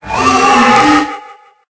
Cri_0886_EB.ogg